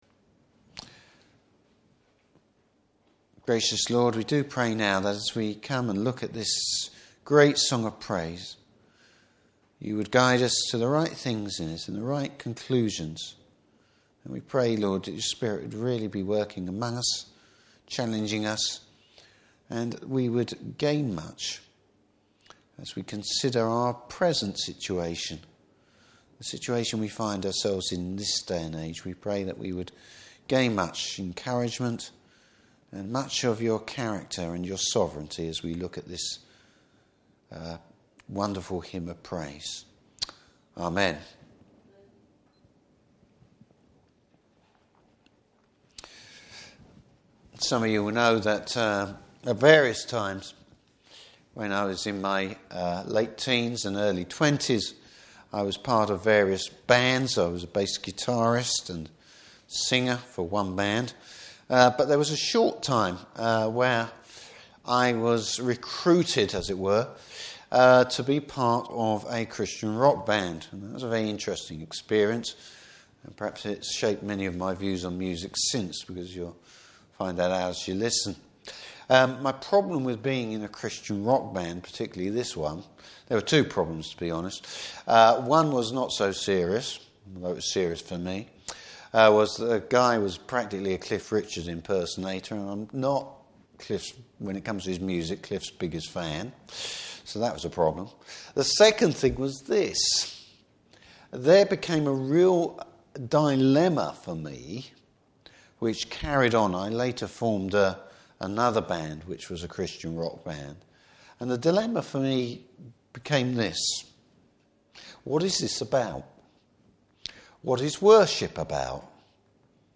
Passage: Judges 5. Service Type: Evening Service Bible Text: Judges 5.